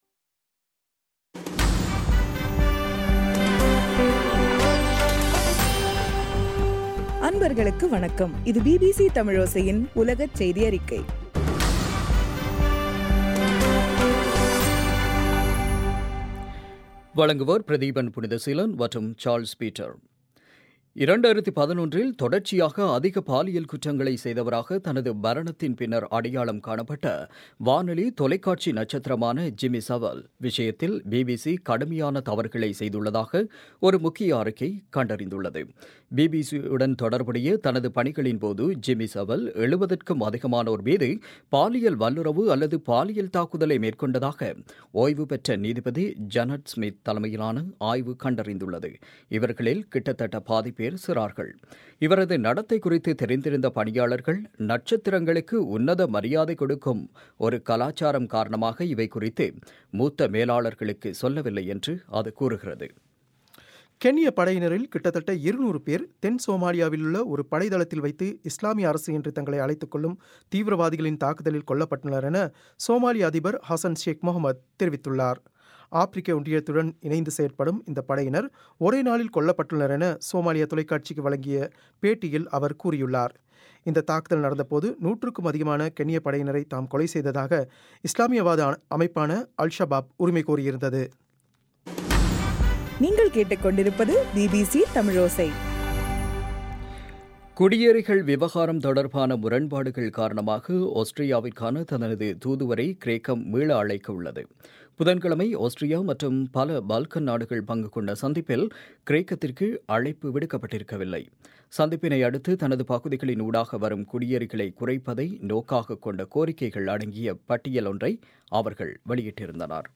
இன்றைய ( பிப்ரவரி 25) பிபிசி தமிழோசை செய்தியறிக்கை